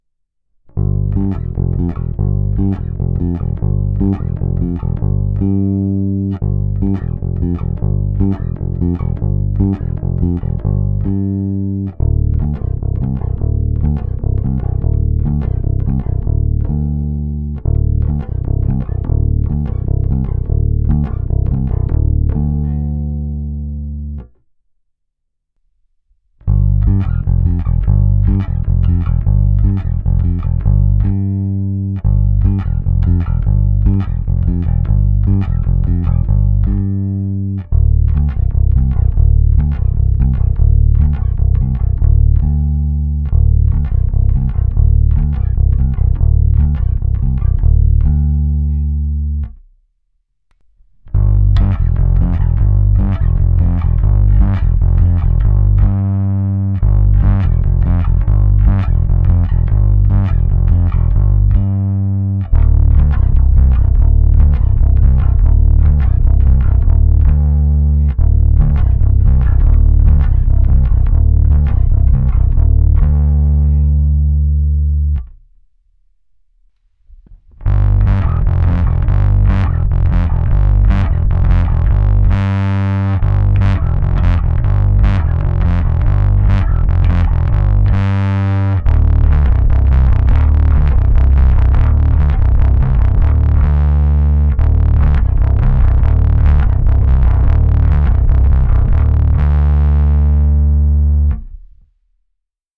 Když se zaměřím na čistý zvuk, tak ten je křišťálově čistý, pevný, konkrétní, absolutně bez šumu.
Následuje nahrávka na pětistrunnou baskytaru Fender American Professional II Precision Bass V s niklovými roundwound strunami Sadowsky Blue Label v dobrém stavu. Nejprve je nahrávka baskytary rovnou do zvukové karty, pak čistý kanál, následuje zkreslení na čistém kanálu vytočením jeho gainu za půlku, a nakonec zkreslený kanál s gainem na dosti nízkou hodnotu, prostě zvuk, který se mi ještě líbil, brutálnější zkreslení jsem nenahrával. První ukázka je taková klasika, druhou jsem udělal zejména kvůli ukázce zvuku na struně H.